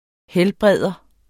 Udtale [ ˈhεlˌbʁεðˀʌ ]